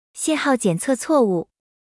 audio_traffic_error.wav